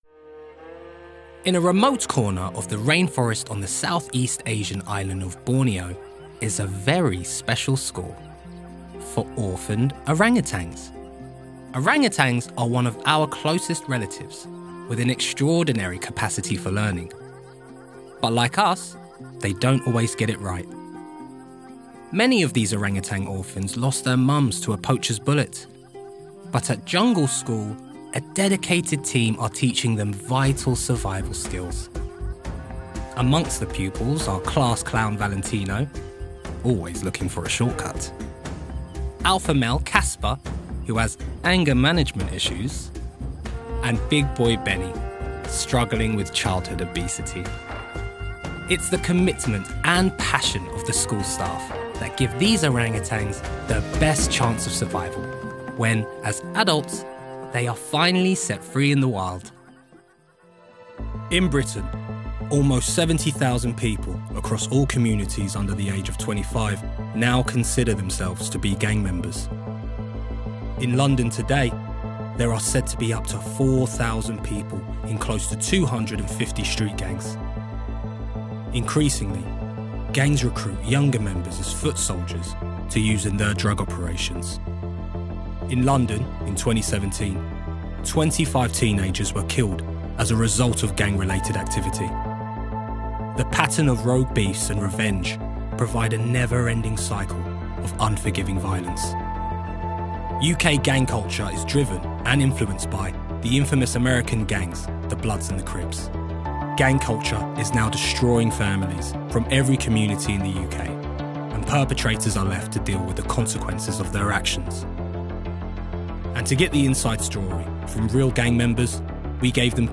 Narration Reel
• Native Accents: London, Cockney, Caribbean, Jamaican
The ultimate London boy-next-door, he oozes confidence while imbuing any script with an audible smile.